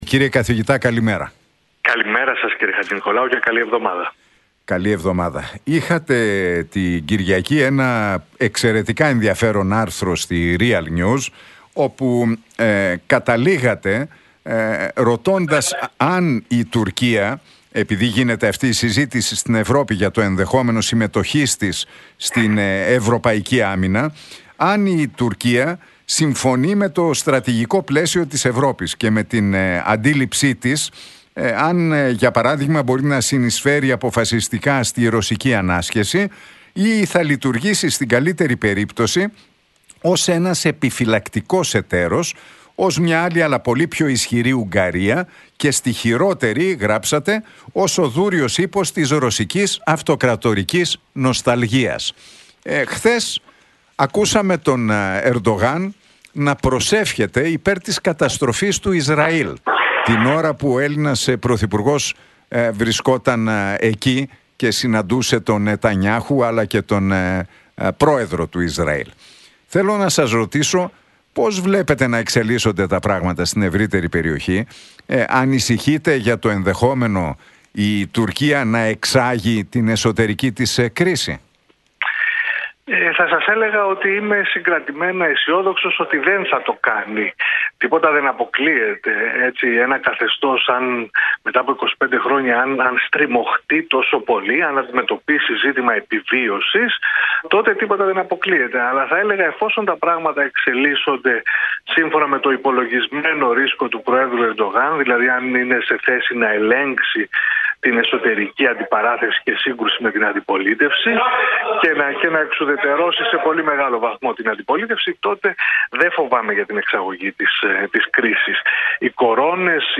στον Realfm 97,8 και την εκπομπή του Νίκου Χατζηνικολάου